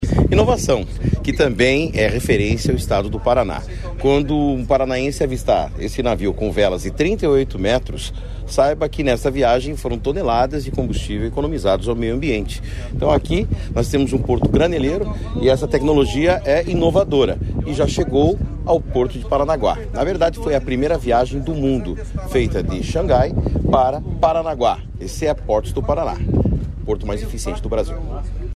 Sonora do secretário de Infraestrutura e Logística, Sandro Alex, sobre o primeiro navio sustentável do mundo, que está no Paraná